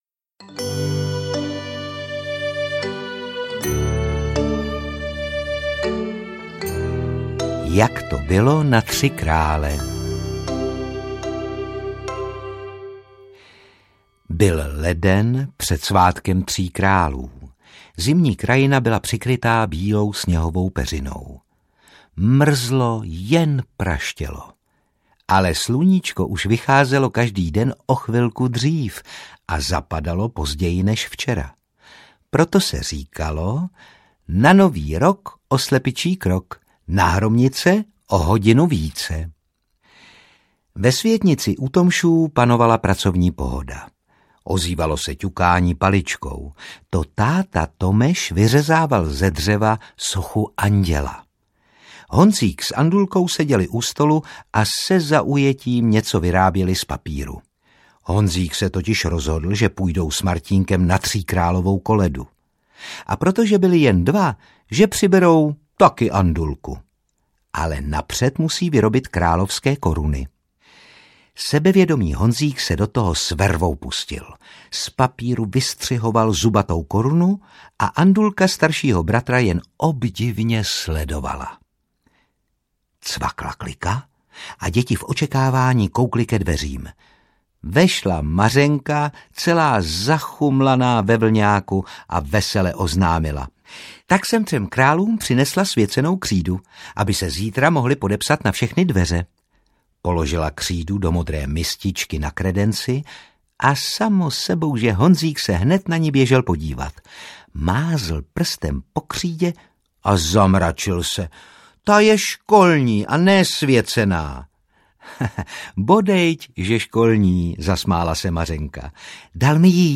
Chaloupka na vršku audiokniha
Ukázka z knihy